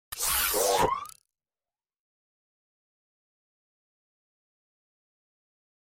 جلوه های صوتی
دانلود آهنگ ربات 29 از افکت صوتی اشیاء
دانلود صدای ربات 29 از ساعد نیوز با لینک مستقیم و کیفیت بالا